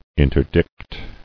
[in·ter·dict]